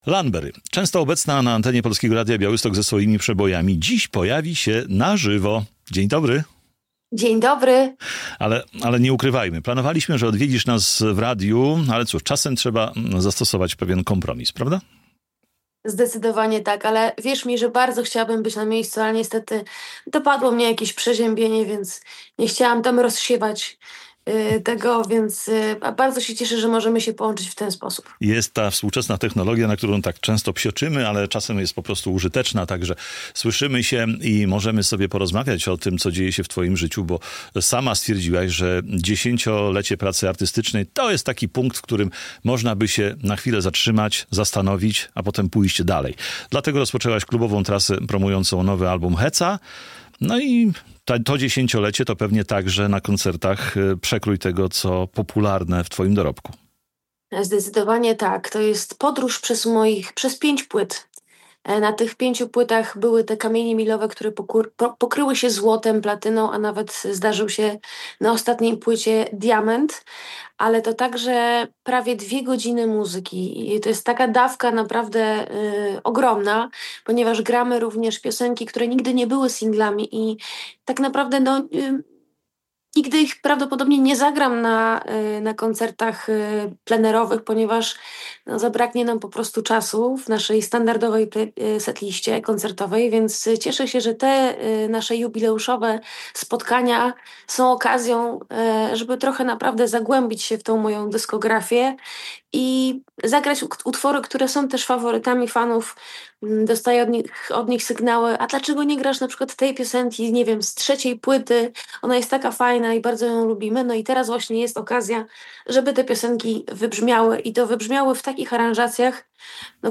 Radio Białystok | Gość | Lanberry (Małgorzata Uściłowska) - piosenkarka, kompozytorka, autorka tekstów